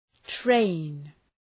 Προφορά
{treın}